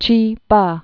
(chē)